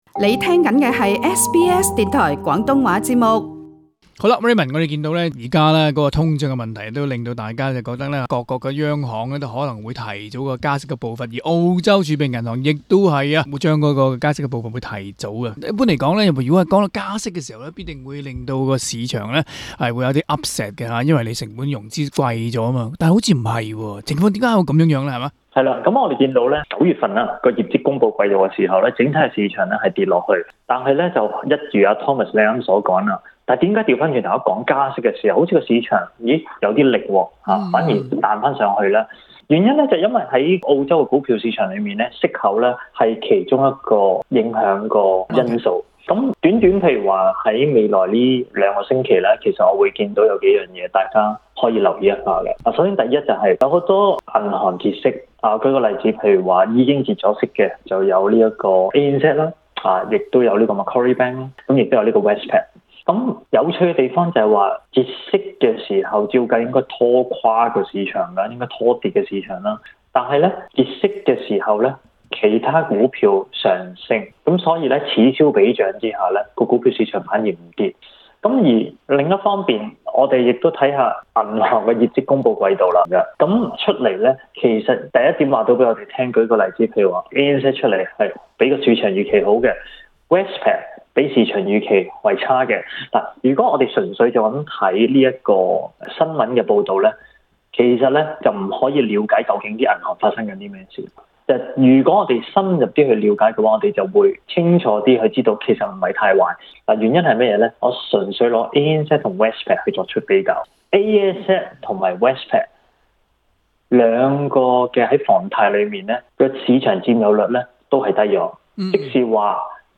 详情请收听今日的访问节目。